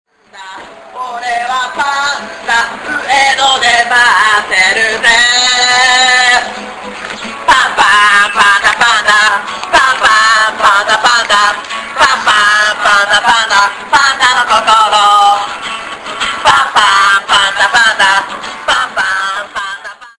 Train station become a hive of youth activity in the evenings.
singing.mp3